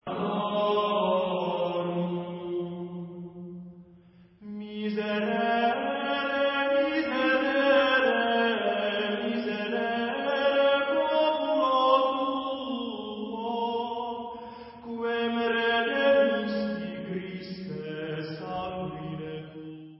Litaniae